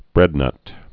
(brĕdnŭt)